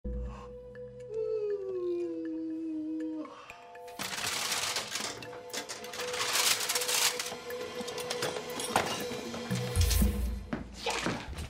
Réponse : House fait tomber des CD, des dossiers et d'autres objets alignés comme dans un domino géant (2x06 Spin)